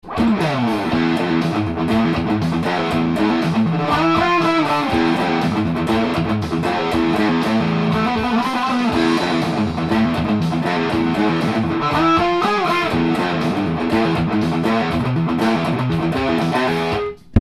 更に低音弦チェックの為　ヘビーなリフも弾いてみました。
やはりギター本体の重量が凄まじいので低音が非常に出て
ブリッジミュートをしながら弾くとかなり気持ちよく演奏できます。